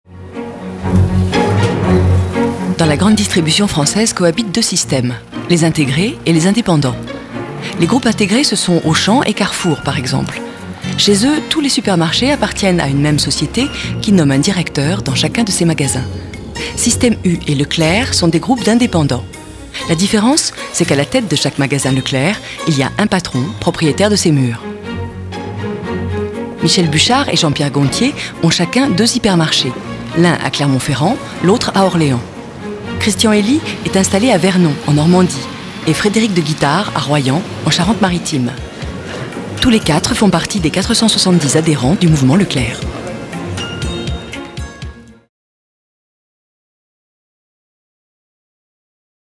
Voix off
institutionnel Alsthom anglais